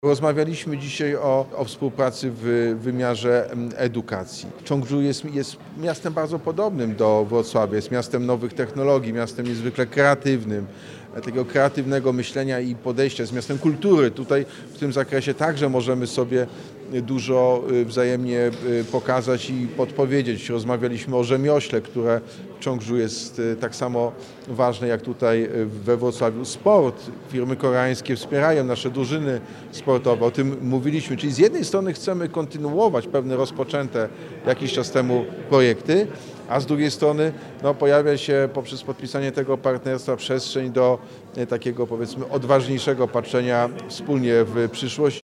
W Sali Wielkiej Starego Ratusza doszło do podpisania umowy partnerskiej między stolicą Dolnego Śląska a południowokoreańskim Cheongju.